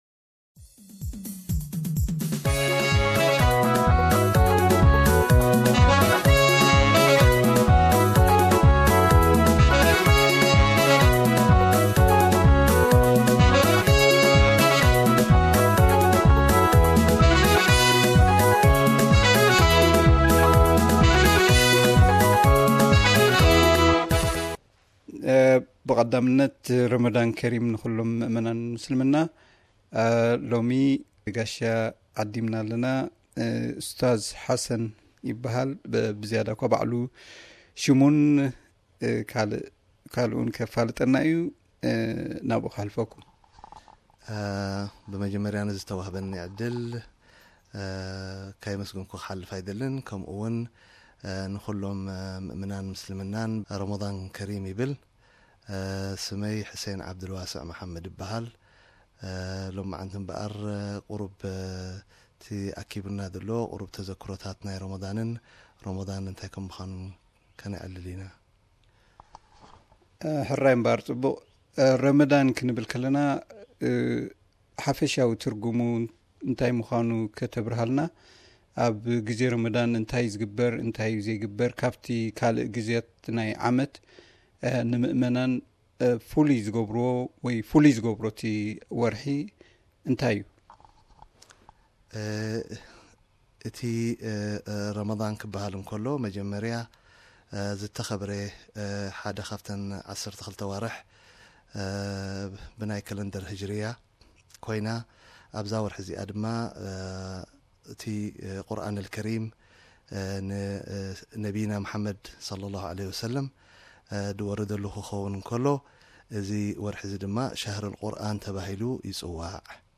Ramadhan interview